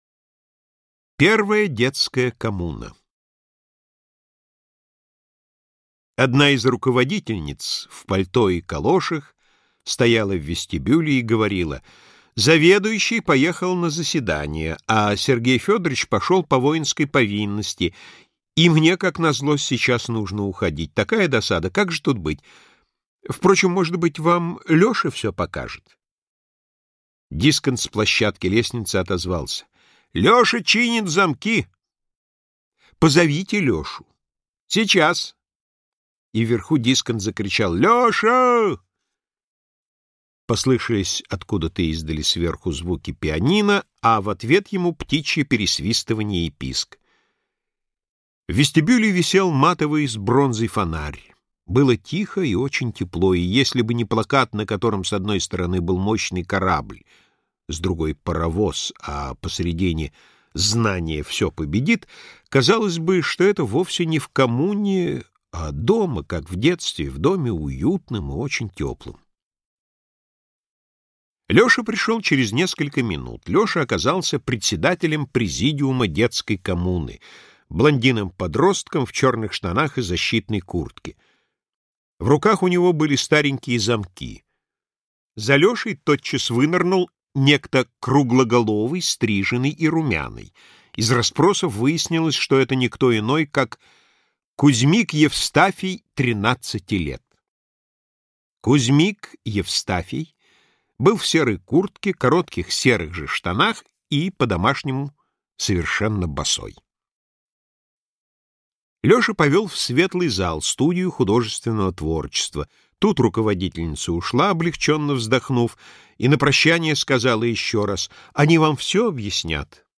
Аудиокнига Москва краснокаменная. Очерки и рассказы | Библиотека аудиокниг